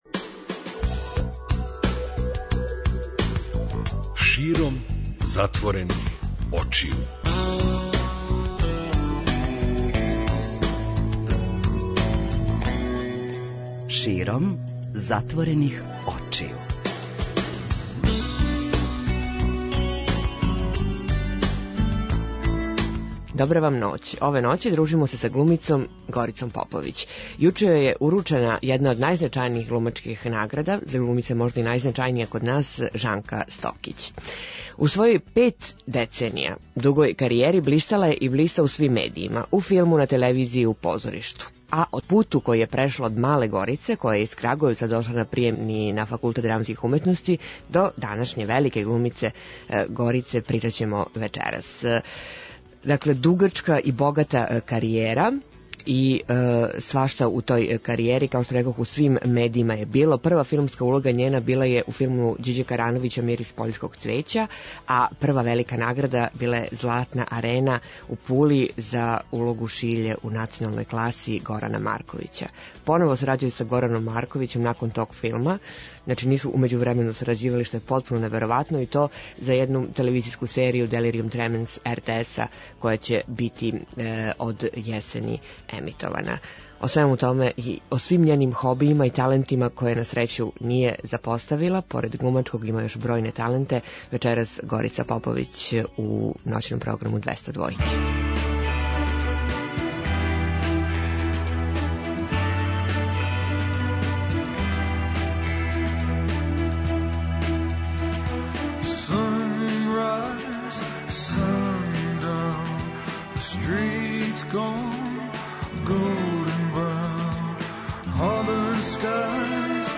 Гост: глумица Горица Поповић